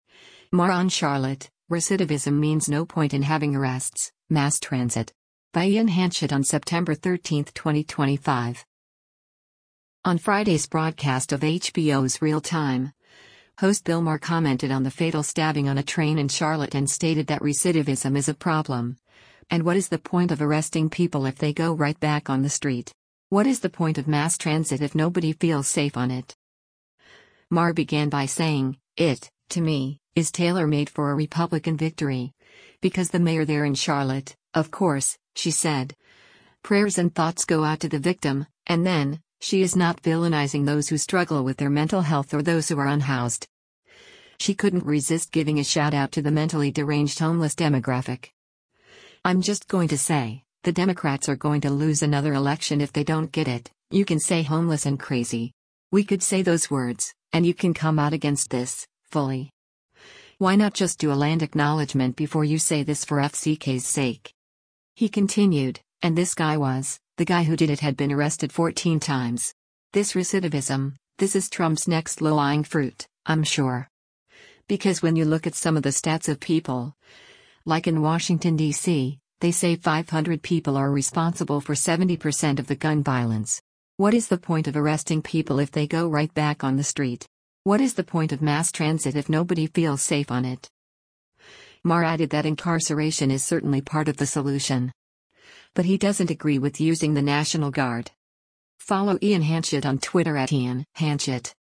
On Friday’s broadcast of HBO’s “Real Time,” host Bill Maher commented on the fatal stabbing on a train in Charlotte and stated that recidivism is a problem, and “What is the point of arresting people if they go right back on the street? What is the point of mass transit if nobody feels safe on it?”